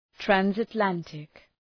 {,trænsət’læntık}